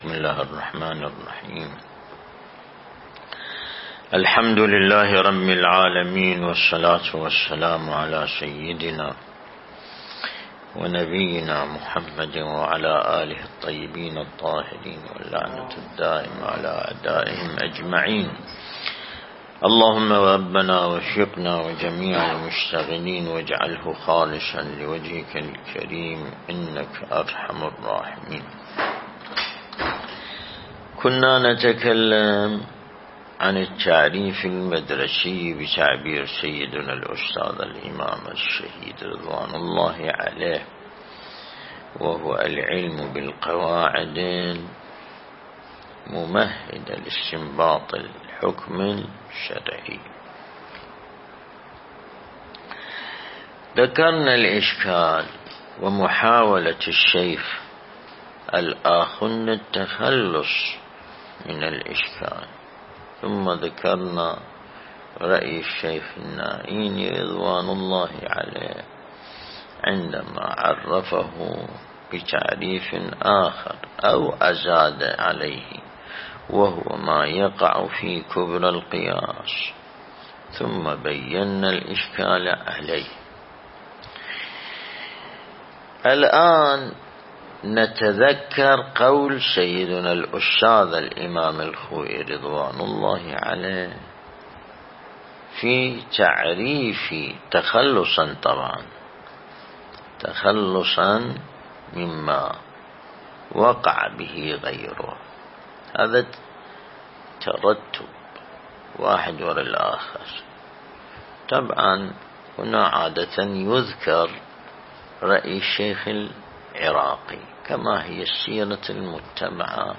درس البحث الخارج الأصول (10) | الموقع الرسمي لمكتب سماحة آية الله السيد ياسين الموسوي «دام ظله»
النجف الأشرف